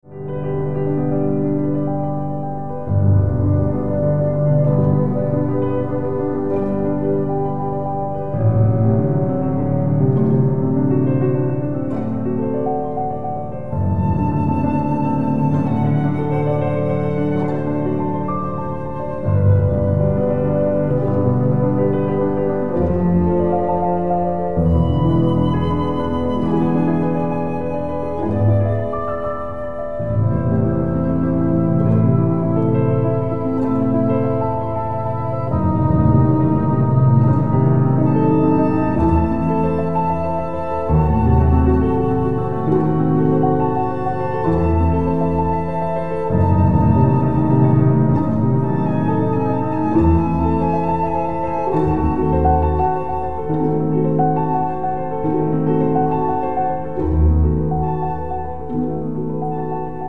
BEETHOVEN, concerto pour piano n3, 02 Largo - MONET (Claude), soleil couchant a Lavacourt@.mp3